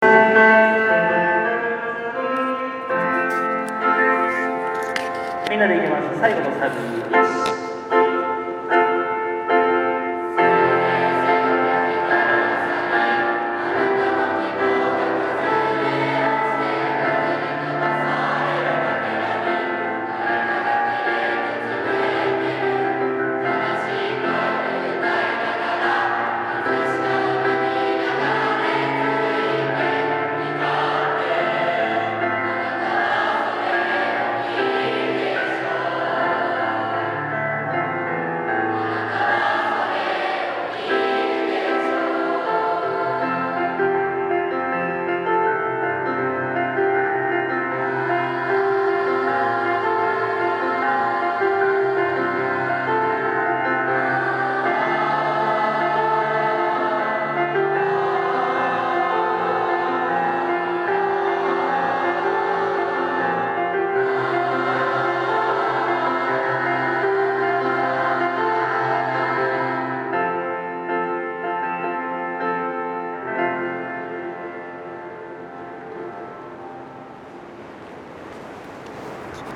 本日は高校体育館ステージにて初挑戦🔰 奥行きのあるスペースで以下にボリュームある歌声をお届けできるか、全校生徒の心を一つに最後の仕上げに励みます✨